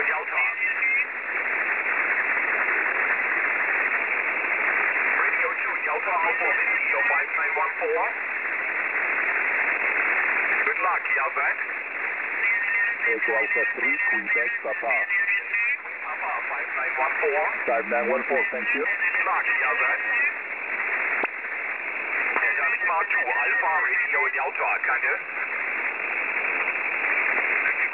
WEB-SDR Aufnahmen von verschieden Standorten